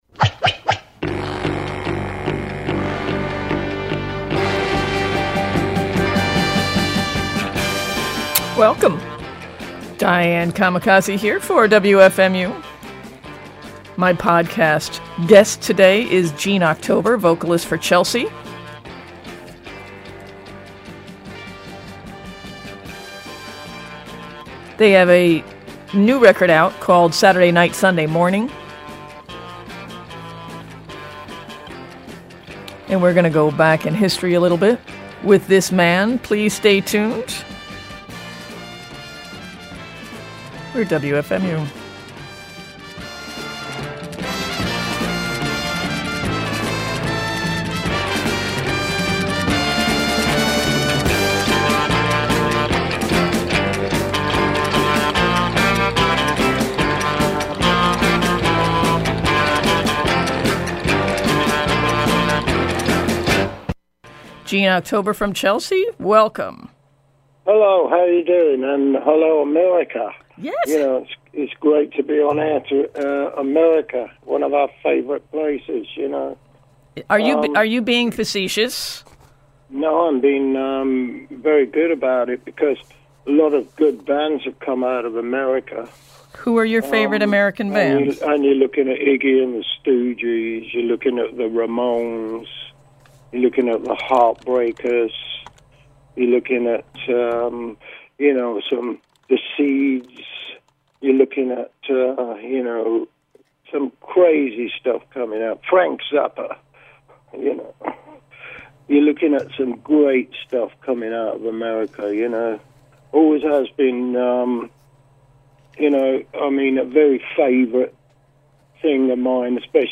Podcast #44: Interview with Gene October of Chelsea from Jul 27, 2015